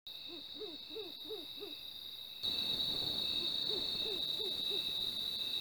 Mottled Owl (Strix virgata)
Location or protected area: Reserva Natural El Puente Verde
Condition: Wild
Certainty: Observed, Recorded vocal